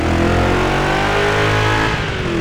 Index of /server/sound/vehicles/lwcars/buggy